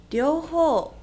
a 両方（りょうほう）＝　Both
• Reading: The on’yomi readings of the kanji
6-ryouhou.mp3